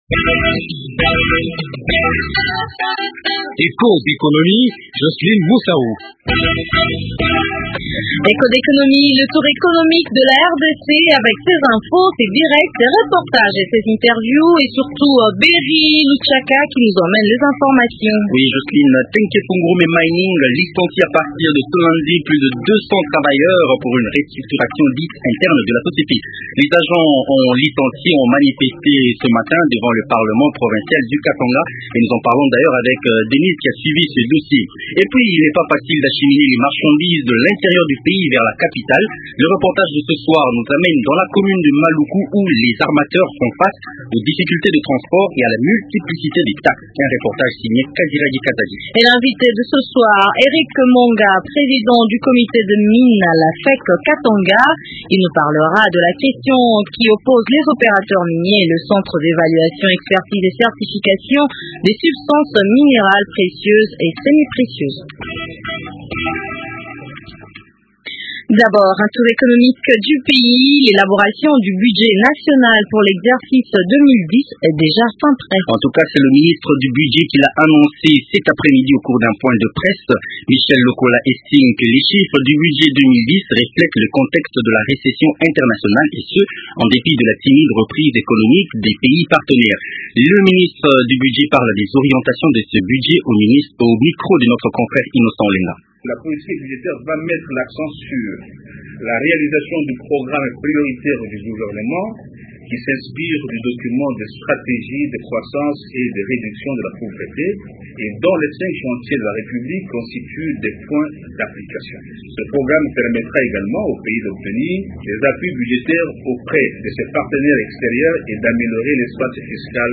Difficultés de transports et multiplicité des taxes. « Echos d’économie » est dans la commune de maluku à Kinshasa, lieux d’embarquement et des débarquements de ces marchandises.